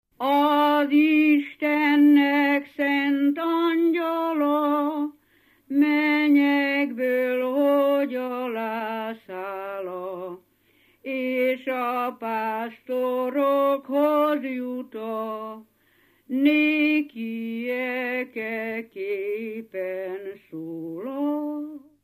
Felföld - Abaúj-Torna vm. - Beret
ének
Stílus: 2. Ereszkedő dúr dallamok
Szótagszám: 8.8.8.8
Kadencia: 8 (3) 5 1